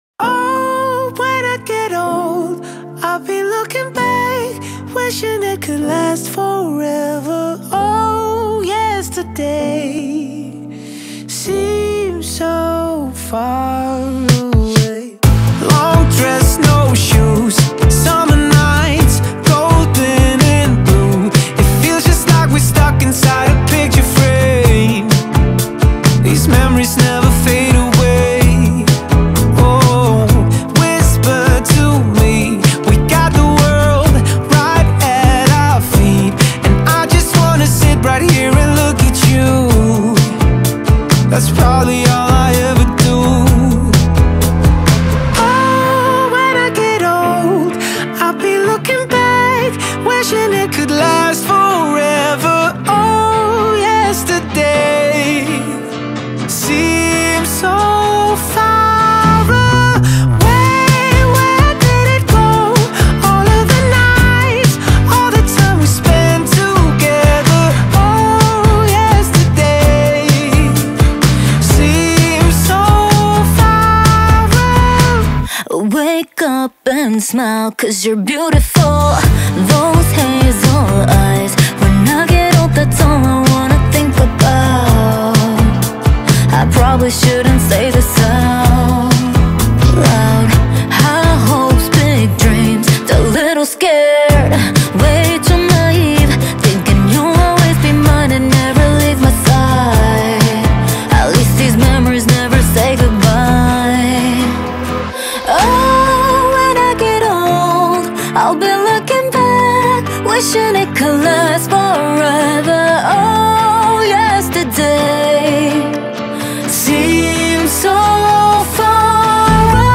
توسط دو خواننده دانمارکی و کره ای خونده شده